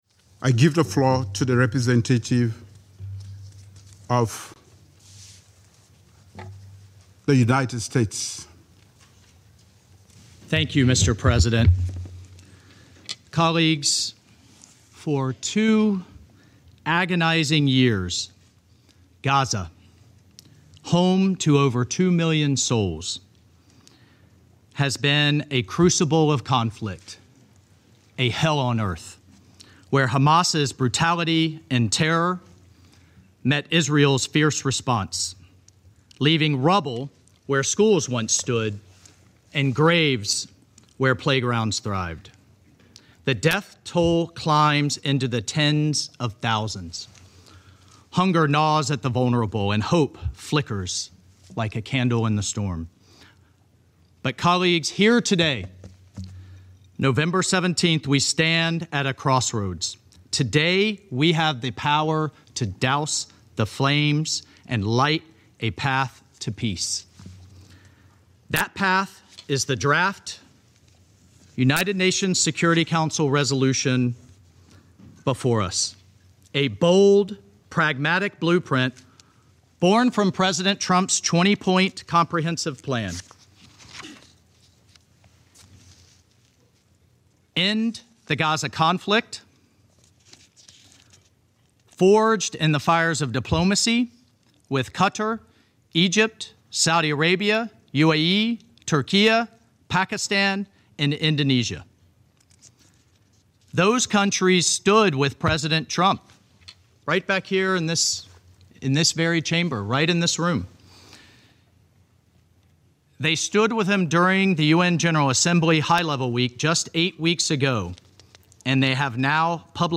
delivered 17 November, UNHQ, New York, NY